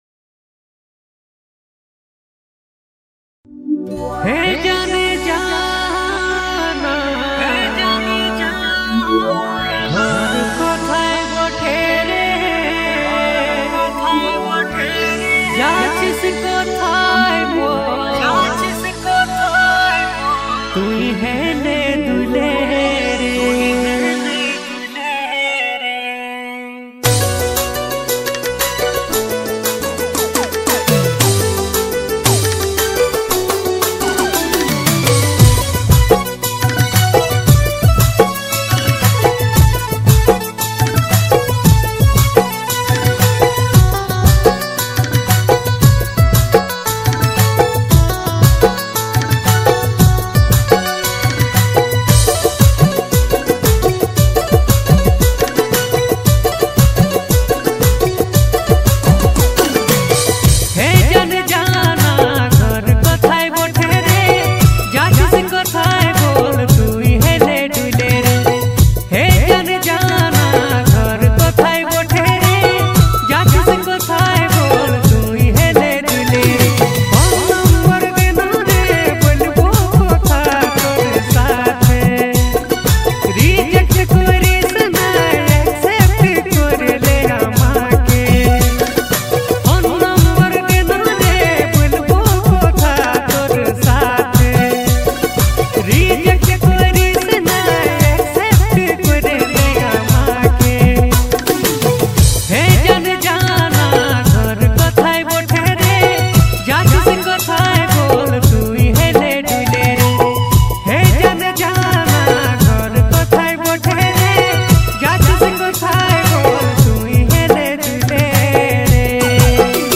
Category: Bengali